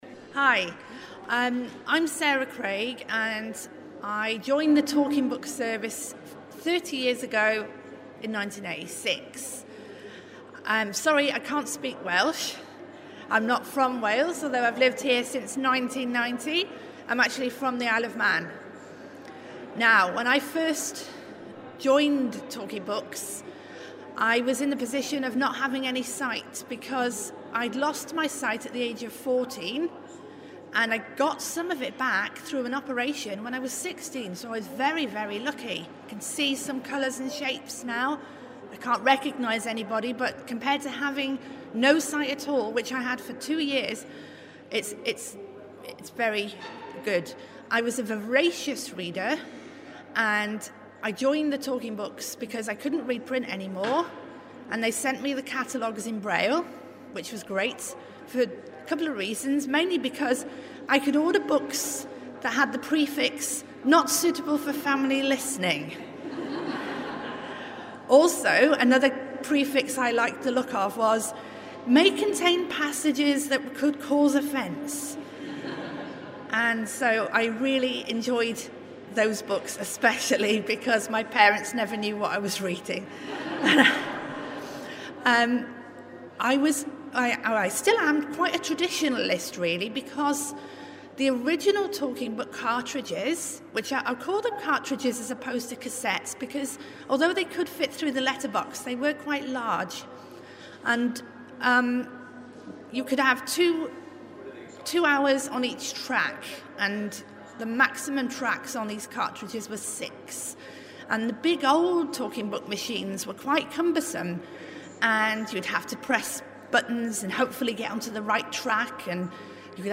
At an RNIB Cymru event celebrating free Talking Books and Roald Dahl